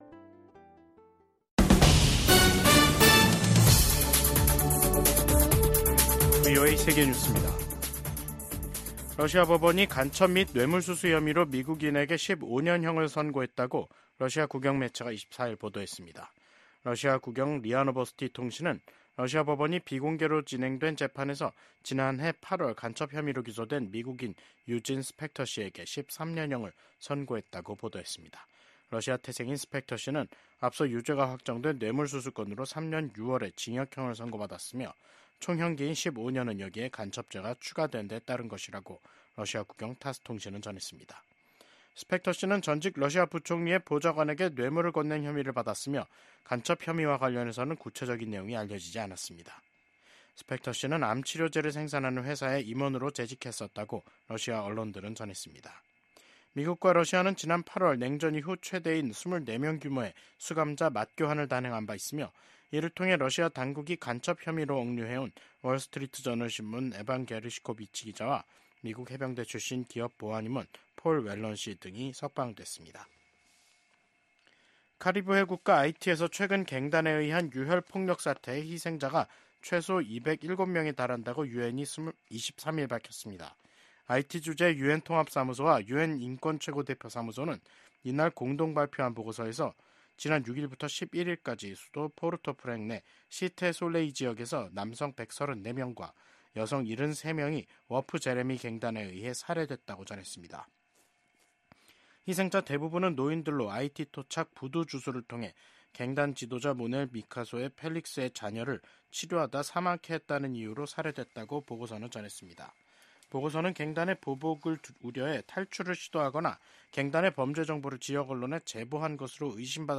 VOA 한국어 간판 뉴스 프로그램 '뉴스 투데이', 2024년 12월 24일 3부 방송입니다. 러시아 당국은 내년 5월 전승절 행사에 북한 군이 참여할 수 있다고 밝혔습니다. 미국과 한국 정부가 최근 한국의 ‘비상계엄’ 사태 등으로 연기됐던 주요 외교, 안보 일정을 재개하기로 합의했습니다. 미국의 한반도 전문가들은 한국에서 진보 정부가 출범하면 ‘진보적 대북 정책’을 실현하기 위해 동맹을 기꺼이 희생할 것이라고 전망했습니다.